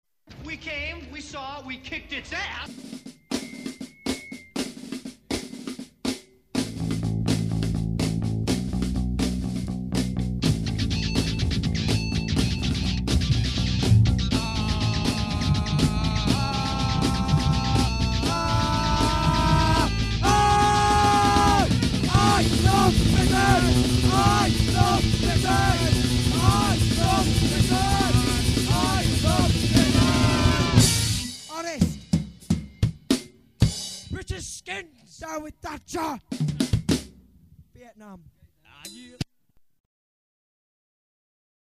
----East coast/West coast Hardcore, Gregorian Chant----